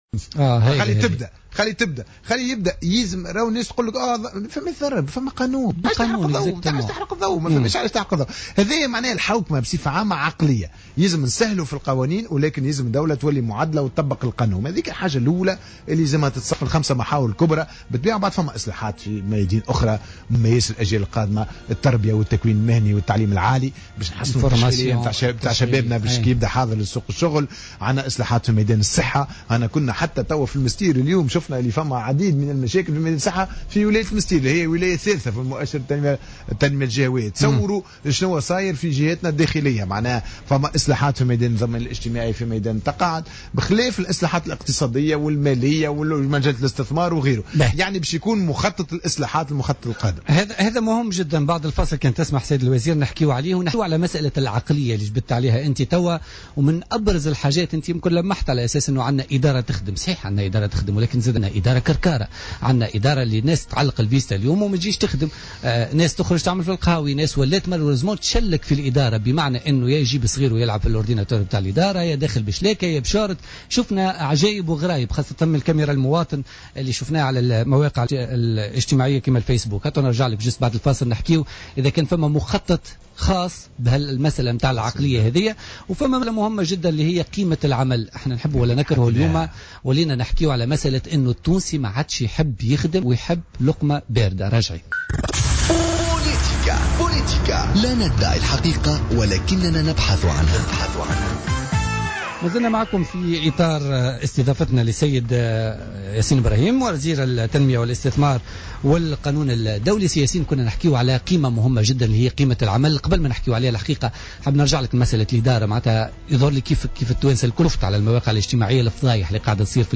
حوار خاص مع وزير التنمية والتعاون الدولي ياسين ابراهيم